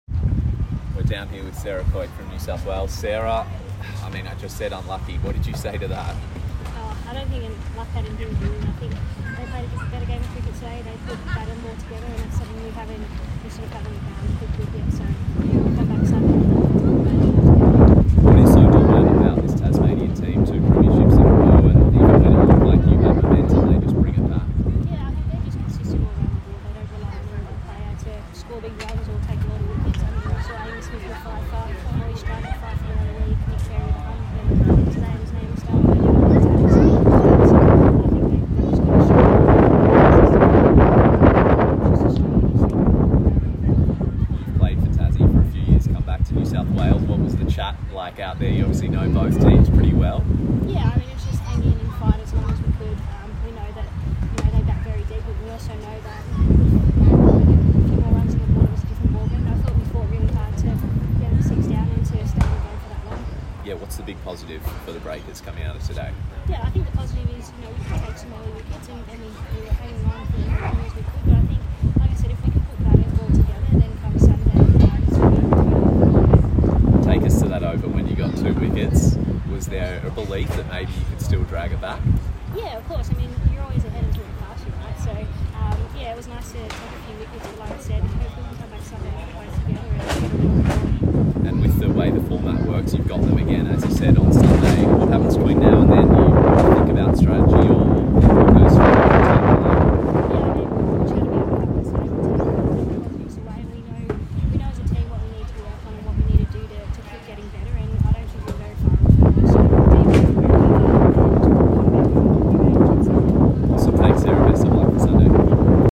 NSW’s Sarah Coyte speaks following clash with NSW Breakers
NSW’s Sarah Coyte (3-21) post-match against NSW Breakers at Cricket Central.